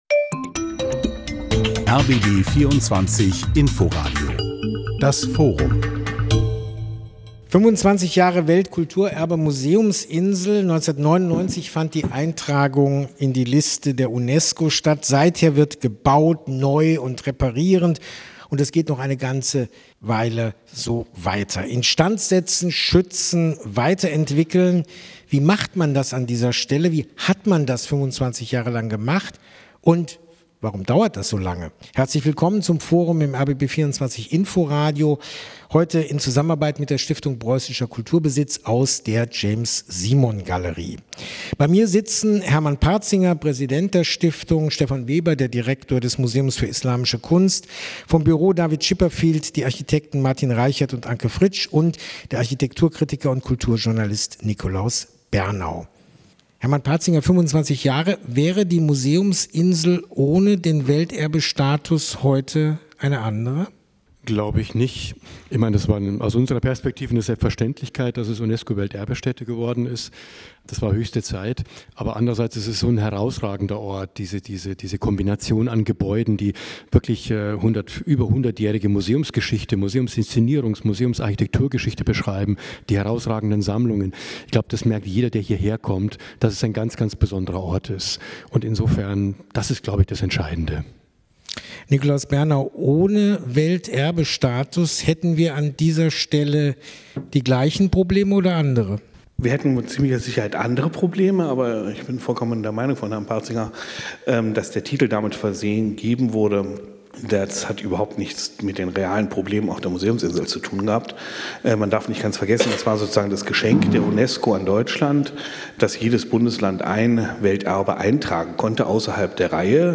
Auf dem Podium